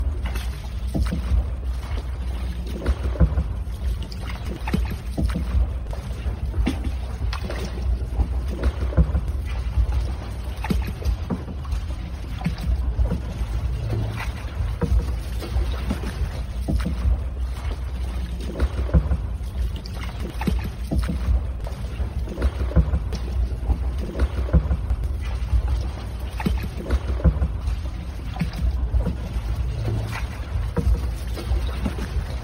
Tiếng Chèo Xuồng, ghe, thuyền… Miền Tây…
Thể loại: Tiếng động
Description: Tiếng chèo xuồng, chèo ghe, bơi thuyền đặc trưng của miền Tây sông nước vang lên nhịp nhàng giữa khung cảnh thanh bình của rừng dừa, kênh rạch. Âm thanh "tách... tách..." của mái chèo khua nước, hòa cùng tiếng nước vỗ mạn xuồng, tạo nên một bản giao hưởng mộc mạc, gần gũi, gợi nhớ đến cuộc sống miền quê Nam Bộ.
tieng-cheo-xuong-ghe-thuyen-mien-tay-www_tiengdong_com.mp3